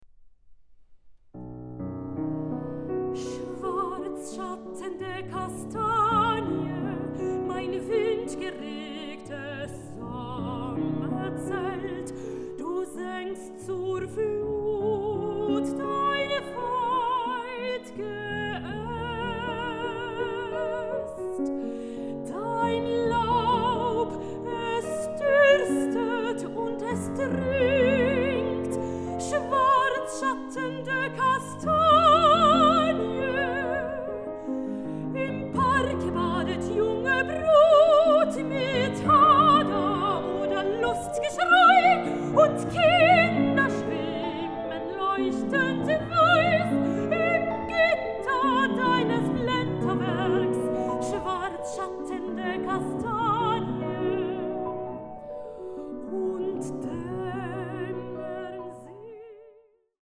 soprano
tenor
piano